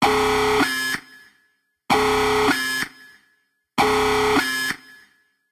siren.ogg